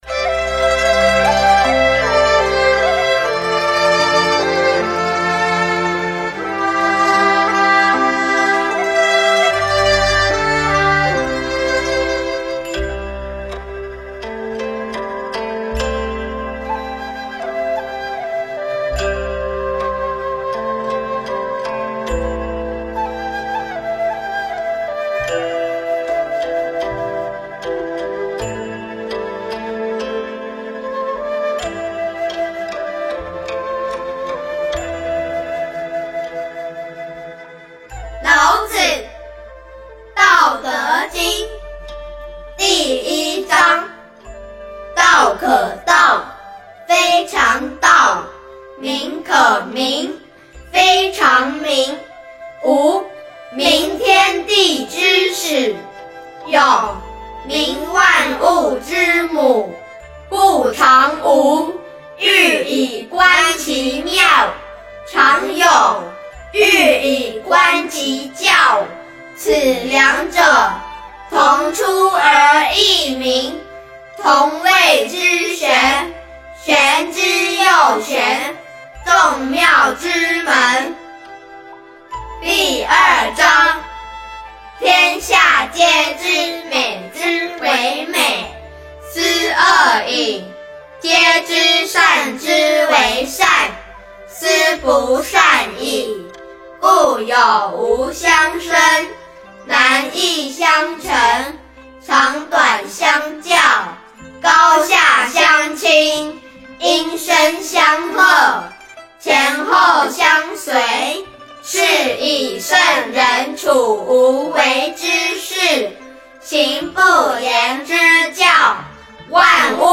道德经--新韵传音 经忏 道德经--新韵传音 点我： 标签: 佛音 经忏 佛教音乐 返回列表 上一篇： 金刚经--僧团 下一篇： 静思佛号(男女合唱版)--新韵传音 相关文章 《妙法莲华经》随喜功德品第十八--佚名 《妙法莲华经》随喜功德品第十八--佚名...